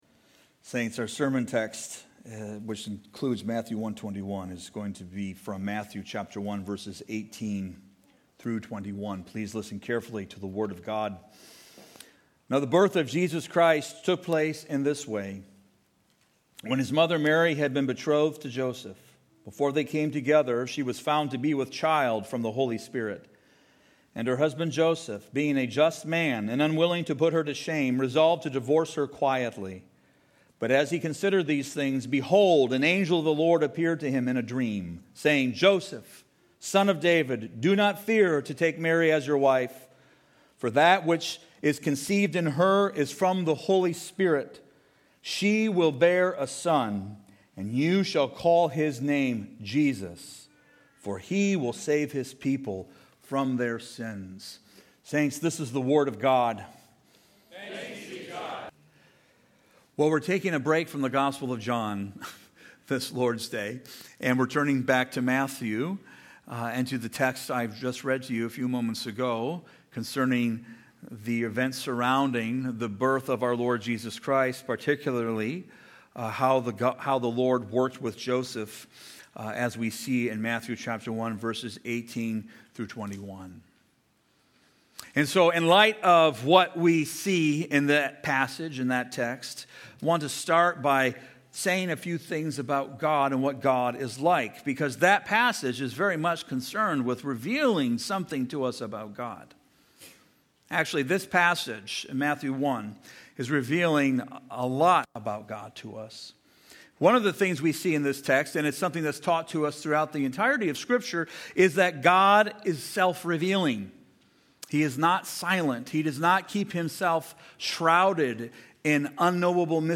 preaches on the redemption achieved by our great Savior.